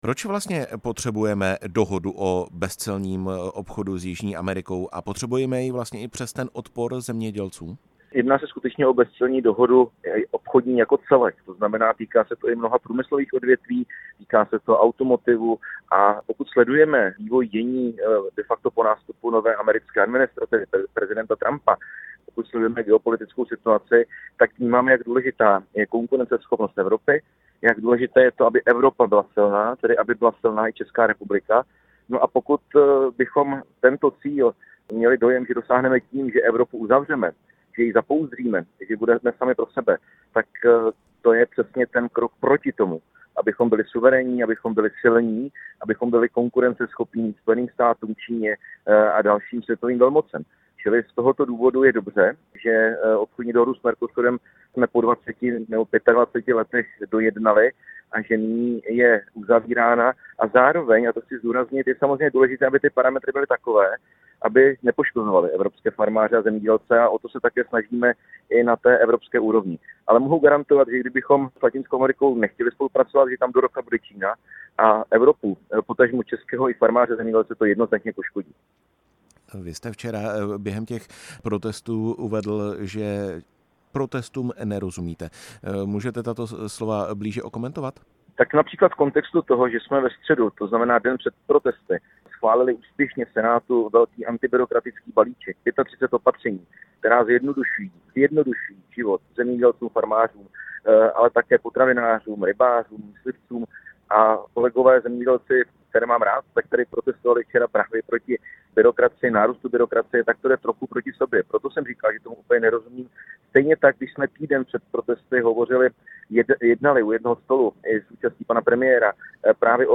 Hostem vysílání Radia Prostor byl Marek Výborný, ministr zemědělství a předseda KDU-ČSL.
Marek Výborný ve vysílání Radia Prostor